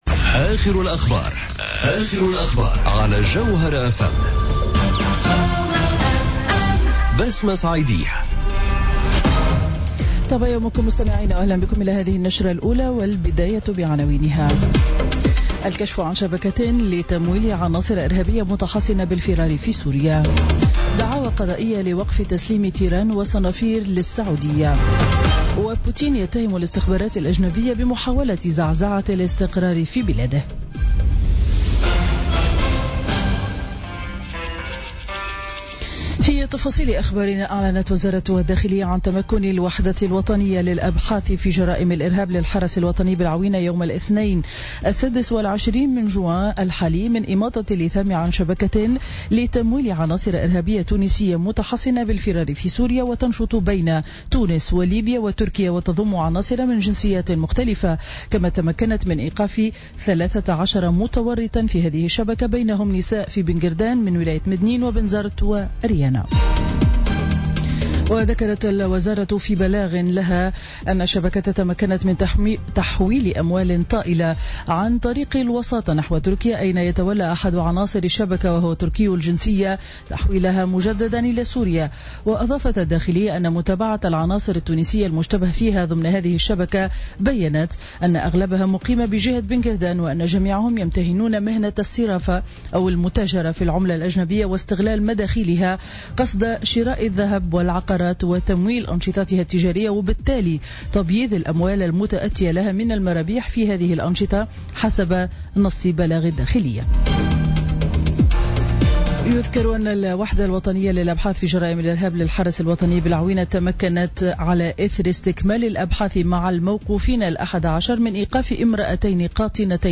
نشرة أخبار السابعة صباحا ليوم الخميس 29 جوان 2017